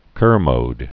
(kûrmōd)